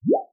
SFX_ItemPickUp_04.wav